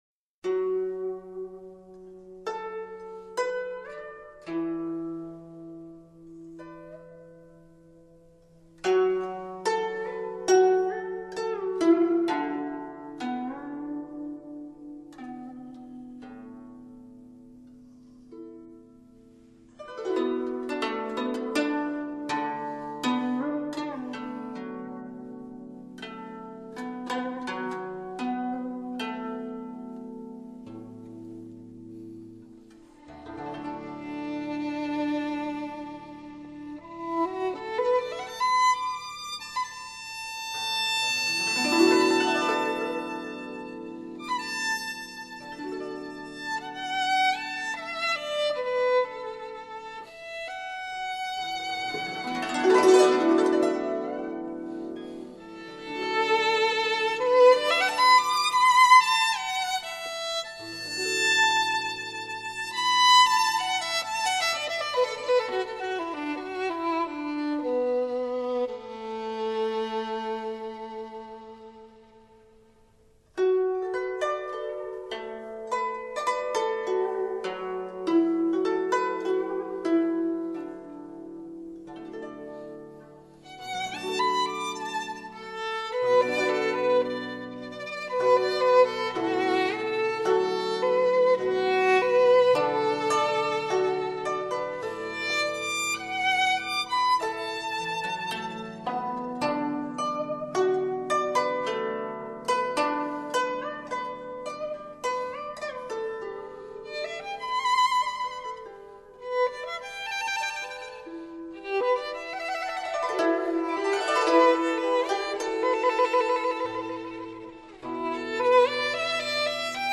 音乐：19首名曲真空管极度Hif录音
(小提琴和古筝)